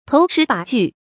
投石拔距 注音： ㄊㄡˊ ㄕㄧˊ ㄅㄚˊ ㄐㄨˋ 讀音讀法： 意思解釋： 見「投石超距」。